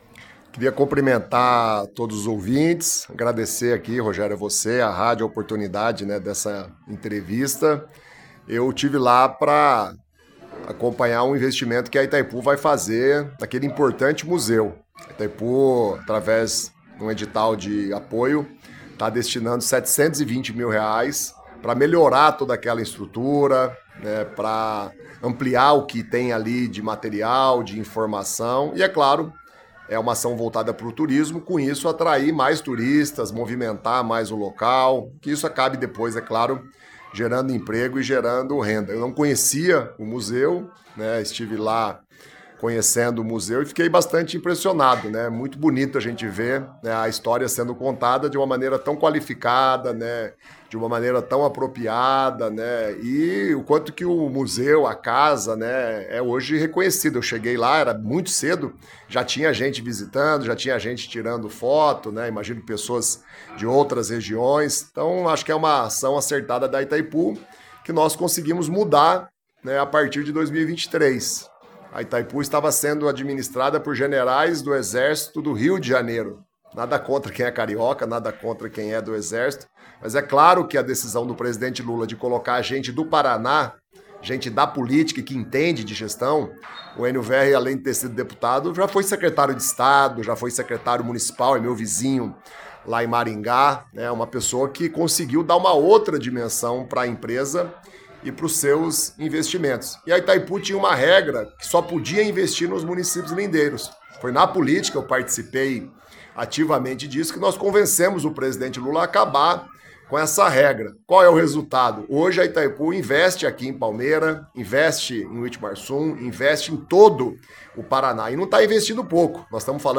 Antes, porém, o deputado esteve na Rádio Cruzeiro do Sul, quando conversamos com ele sobre a programação da visita ao município.